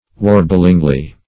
warblingly - definition of warblingly - synonyms, pronunciation, spelling from Free Dictionary Search Result for " warblingly" : The Collaborative International Dictionary of English v.0.48: Warblingly \War"bling*ly\, adv.